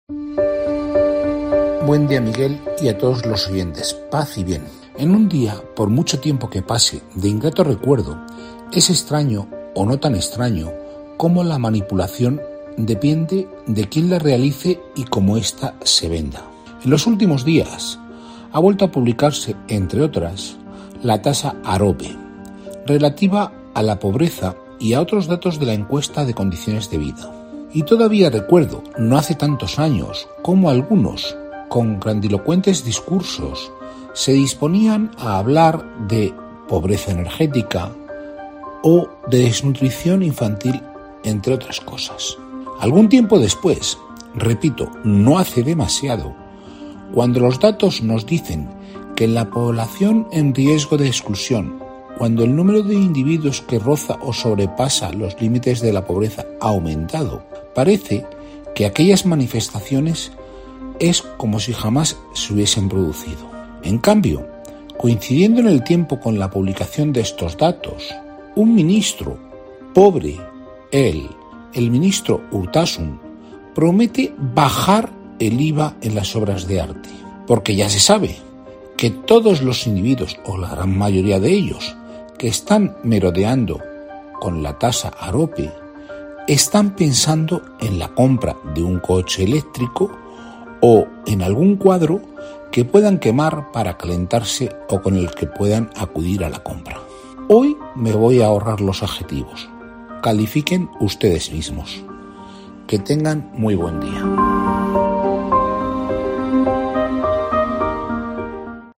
Opinión en COPE Albacete